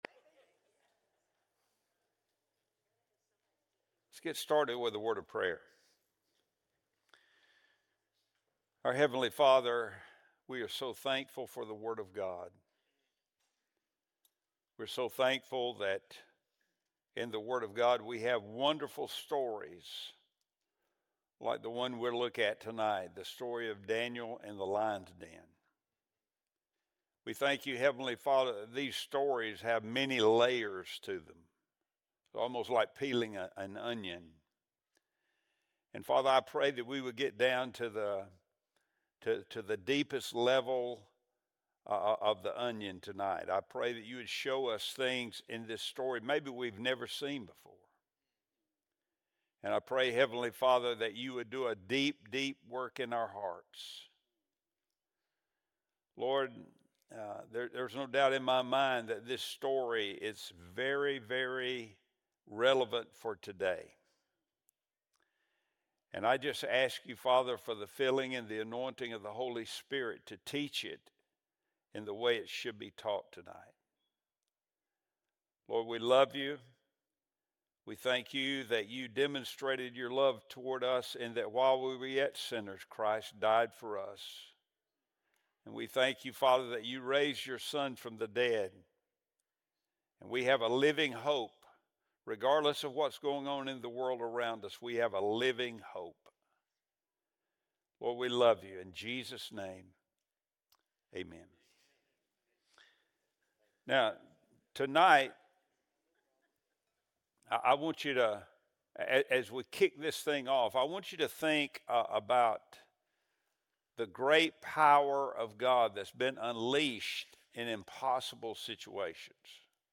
Wednesday Bible Study | October 29, 2025